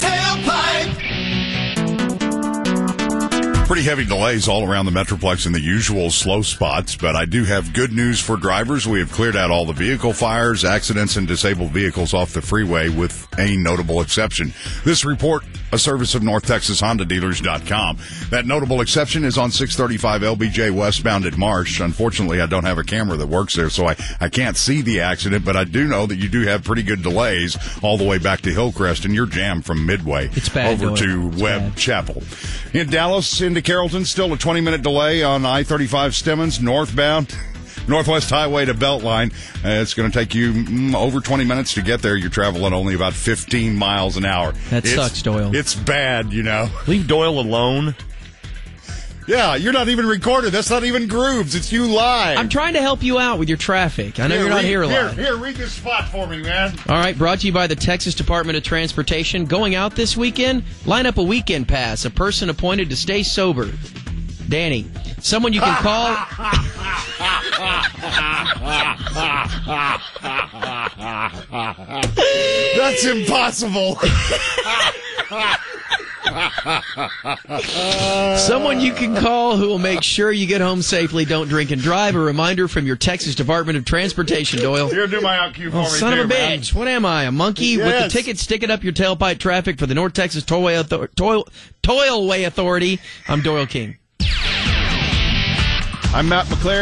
Traffic.
traffic-gone-bad-report.mp3